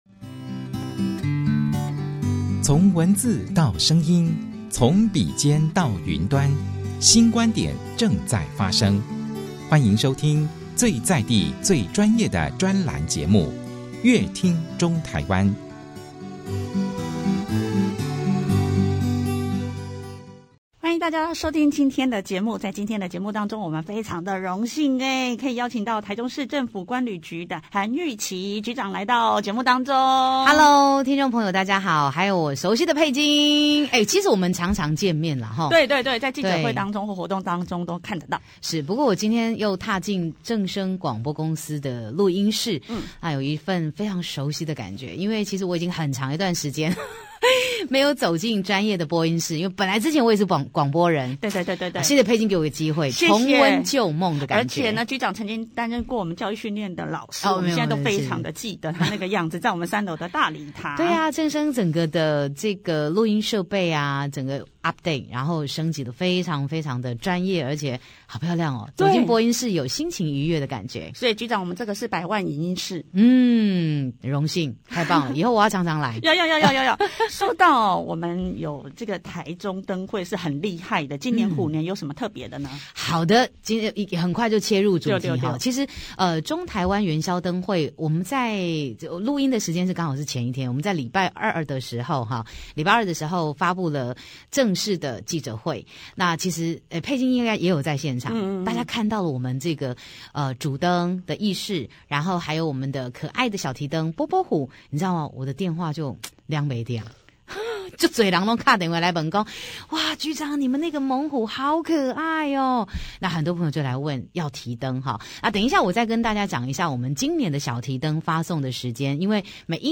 觀旅局長韓育琪上任之後，第一次接受正聲台中台的專訪，邀約大家2/12-2/20為期9天，一起來觀賞號稱是最有森林感的燈展，另外她也預告2/12開幕儀式當天還會有盛大民歌演唱會，邀請超強卡司演出，和大家一起賞花燈。